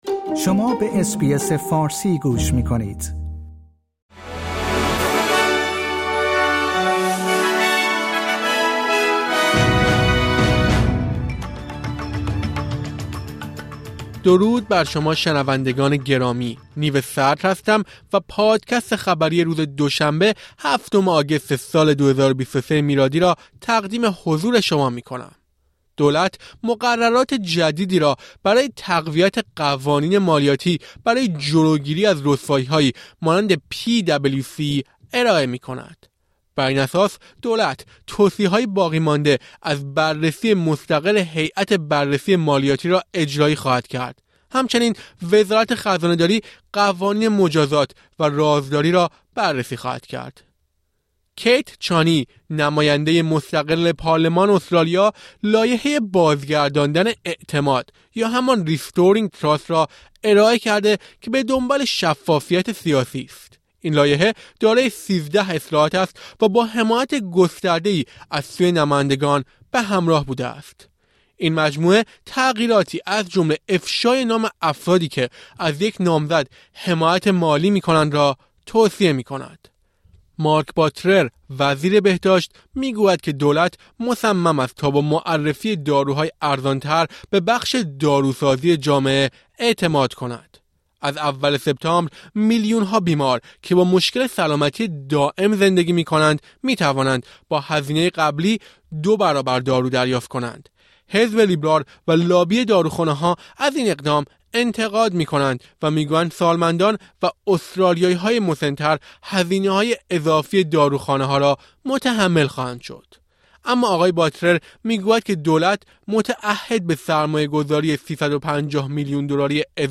در این پادکست خلاصه‌ای از مهمترین اخبار استرالیا در روز دوشنبه چهاردهم آگوست ۲۰۲۳ ارائه شده است.